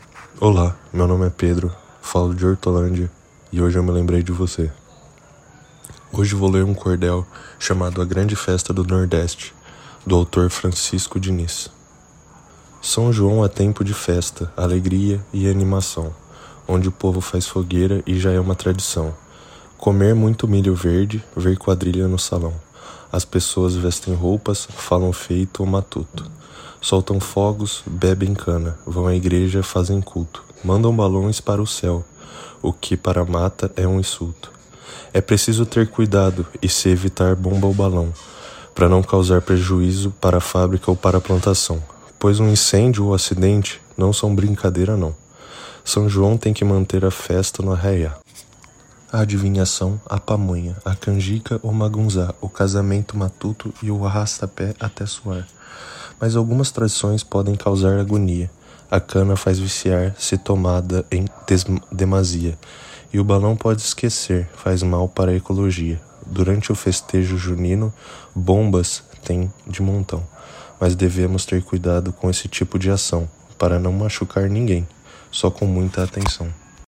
Cordel Português